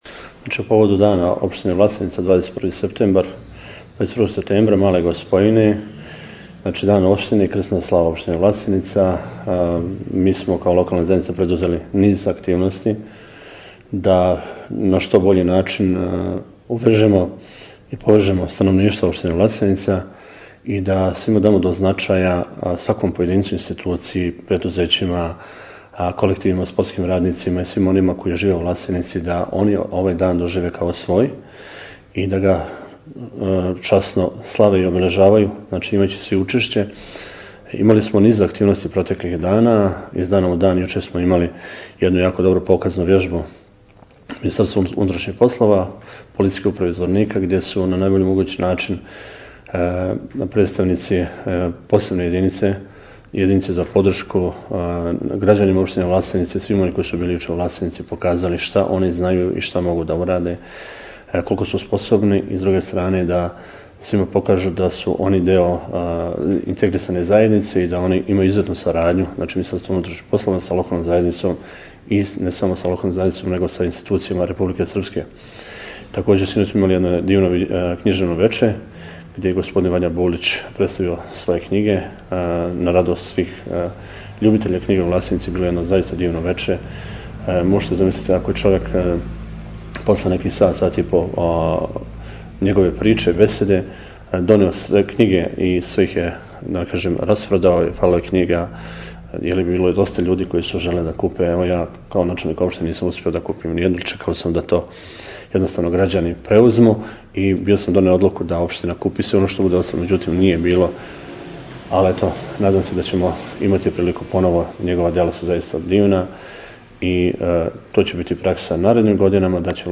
Мирослав Краљевић, начелник општине Власеница – 20.09.2017. тонска изјава поводом Дана општине Власеница